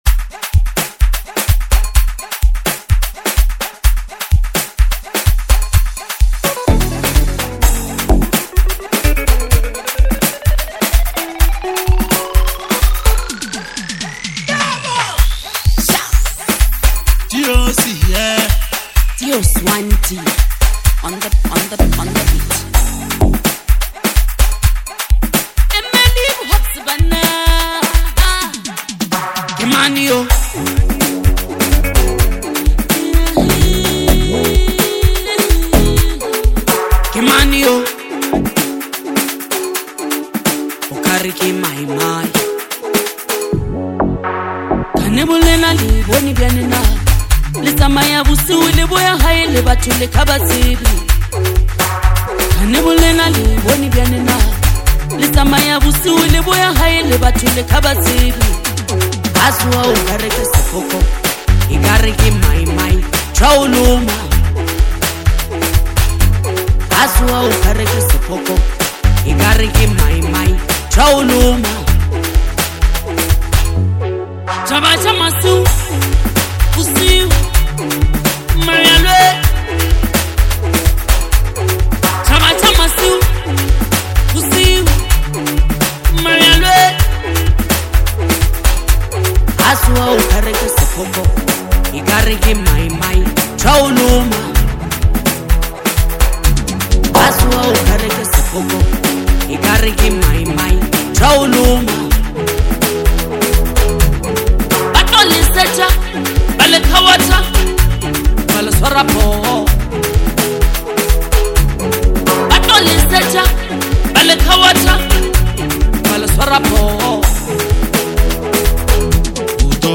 Lekompo
Genre: Lekompo.